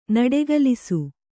♪ naḍegalisu